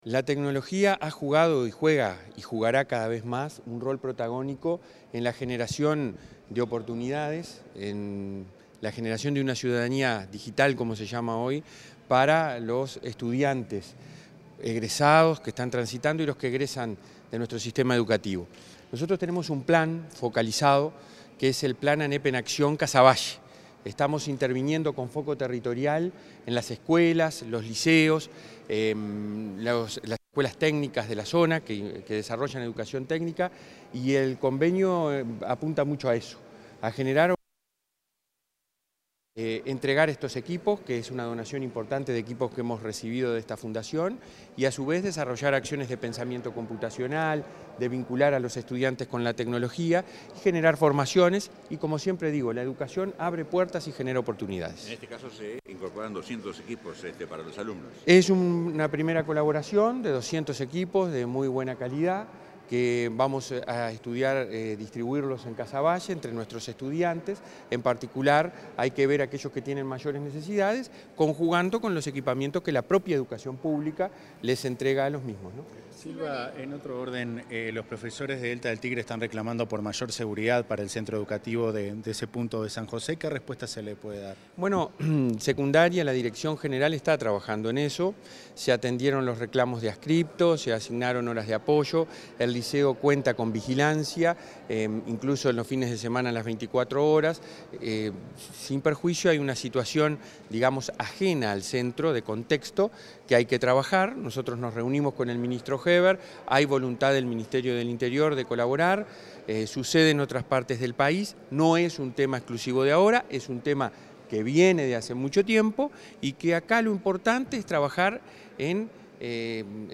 Declaraciones a la prensa del presidente del Codicen de la ANEP, Robert Silva
Tras el evento, el presidente del Codicen, Robert Silva, efectuó declaraciones a la prensa.